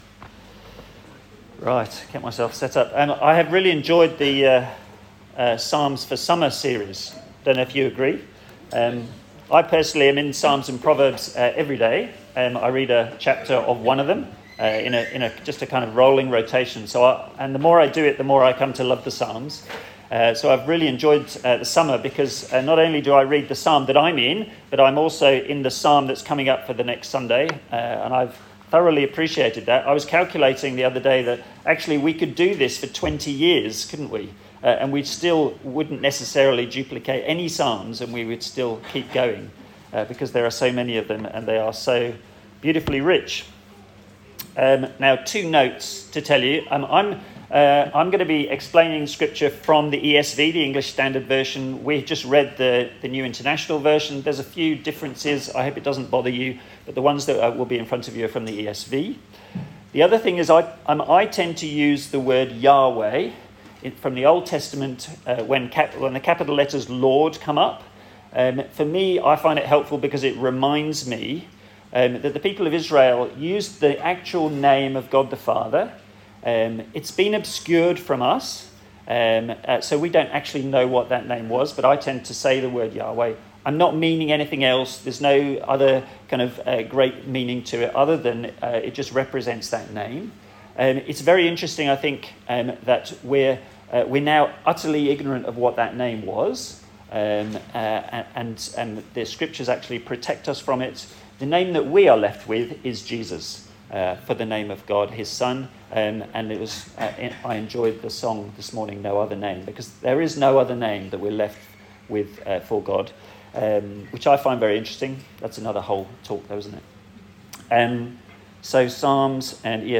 A sermon in the series 'Songs for Summer' featuring the book of Psalms.
Psalm 118 Service Type: Sunday Service A sermon in the series 'Songs for Summer' featuring the book of Psalms.